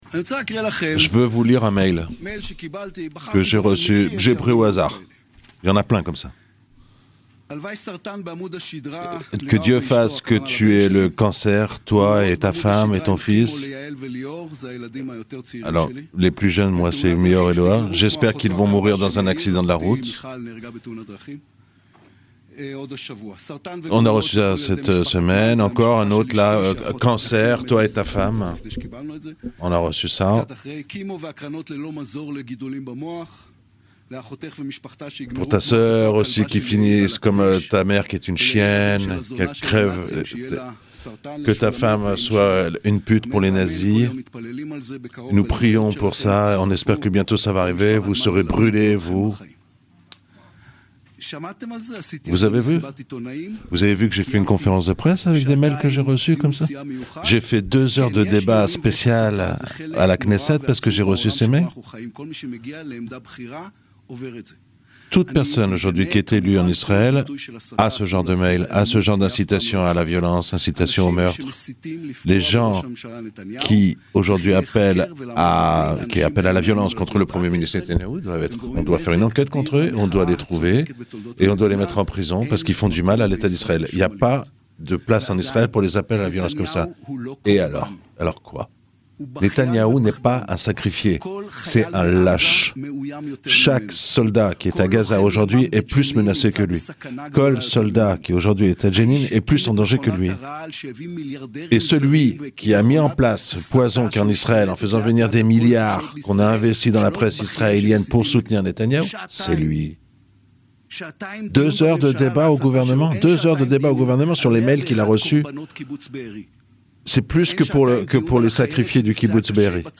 Une volée de bois vert qui intervient après un débat de deux heures au gouvernement hier sur les incitations à la violence contre Netanyahou. Ecoutez l'intervention de Yaïr Lapis traduite en français.